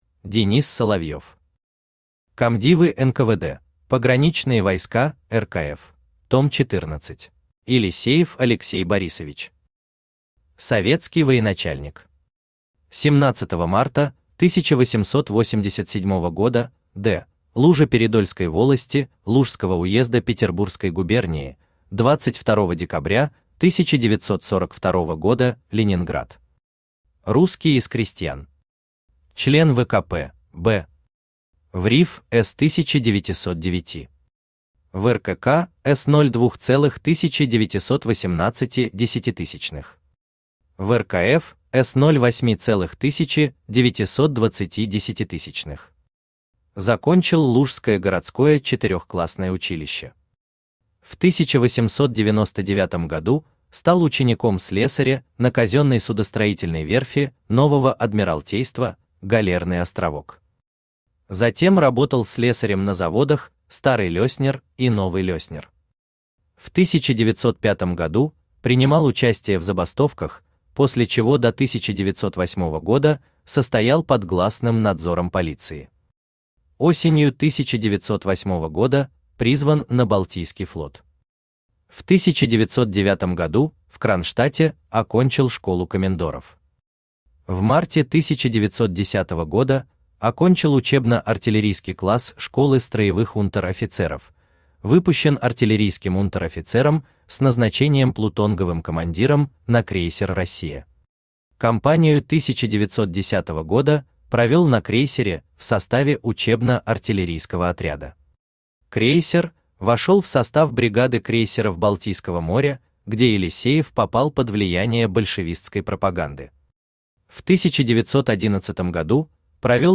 Aудиокнига Комдивы НКВД.
Том 14 Автор Денис Соловьев Читает аудиокнигу Авточтец ЛитРес.